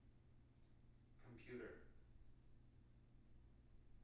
wake-word
tng-computer-303.wav